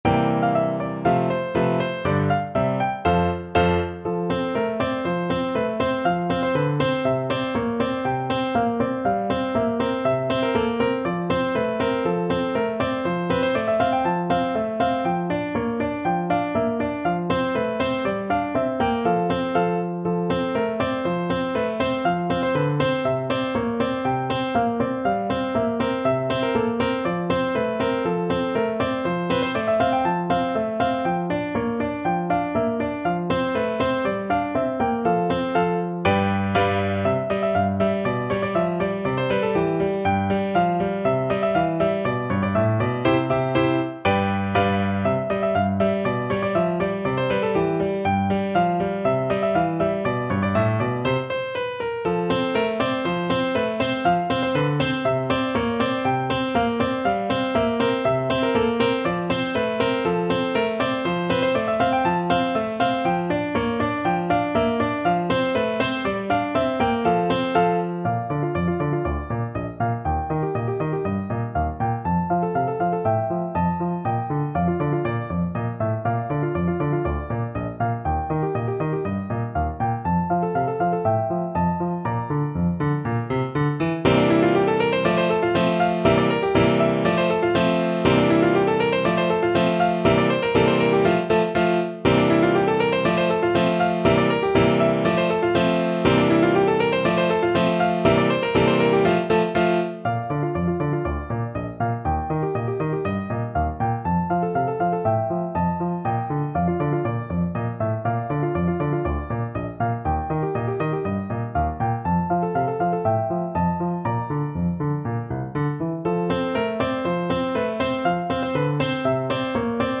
No parts available for this pieces as it is for solo piano.
2/4 (View more 2/4 Music)
= 120 = c.142